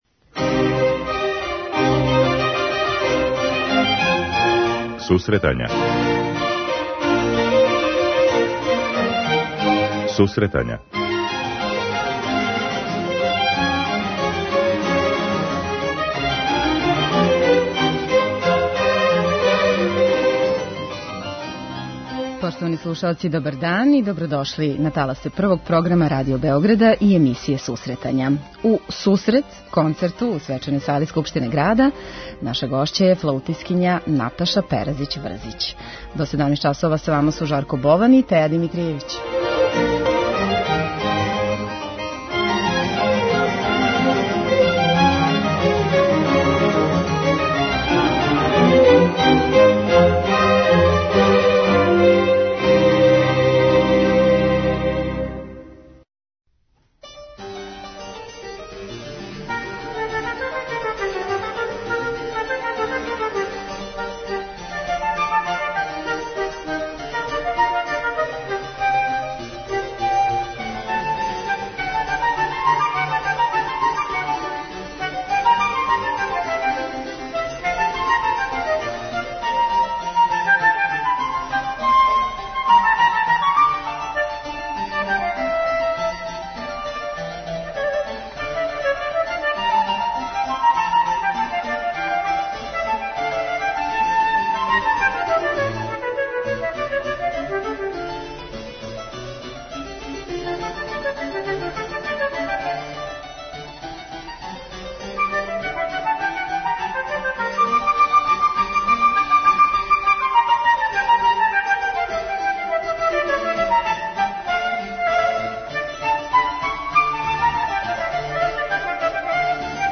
преузми : 10.40 MB Сусретања Autor: Музичка редакција Емисија за оне који воле уметничку музику.